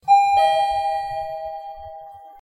b6a80119c2 Main / resources / [standalone] / sh-elevator / html / sounds / arrival.ogg root d84b6e12fd first Commit 2025-06-07 08:51:21 +02:00 23 KiB Raw History